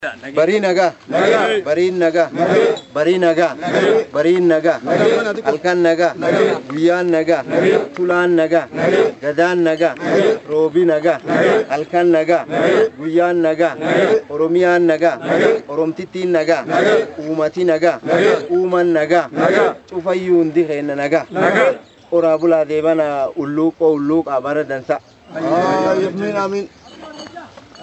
(Qeerroo, Naayirobii, Sadaasa 25,2012) Ayyaana Hulluuqoo kan bara 2012 sirna hoo’aadhaan magaalaa Nairbi Kenya keessatti kabajame.